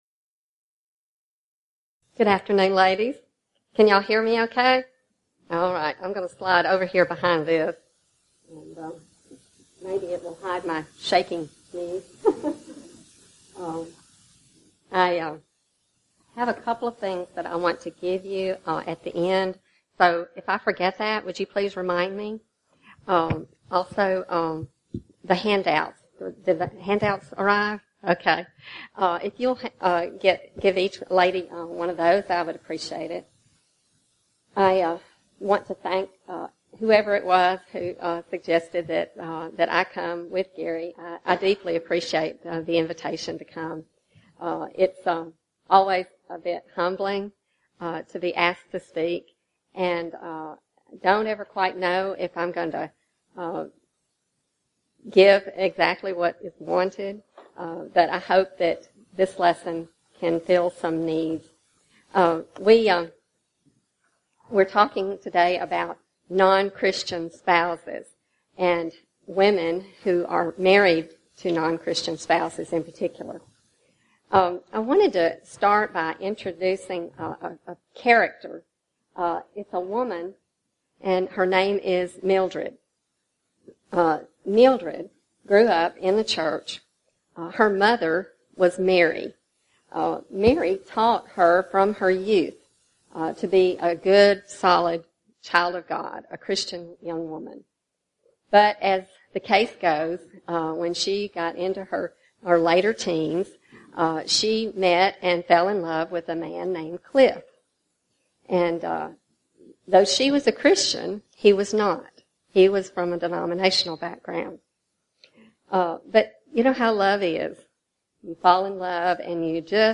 Event: 34th Annual Southwest Lectures